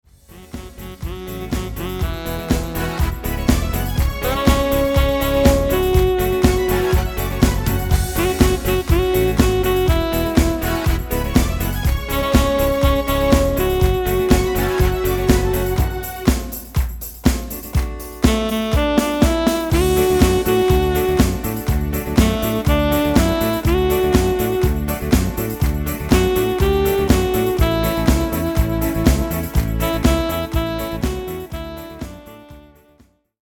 Instrument Tyros5